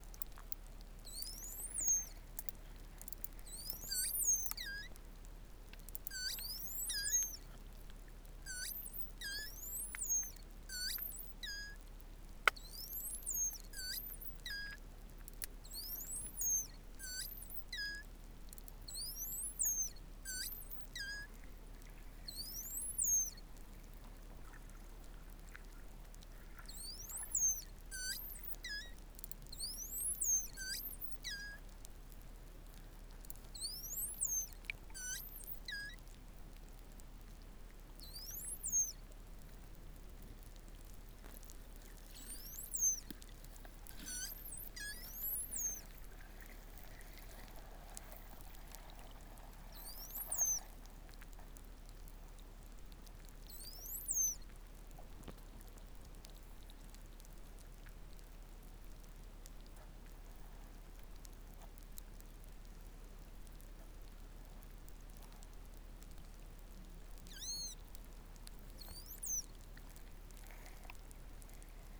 Young Pilot Whale whistles recorded at the Marine Mammal Conservancy.
Pilot_Whale_Young_Whistle.wav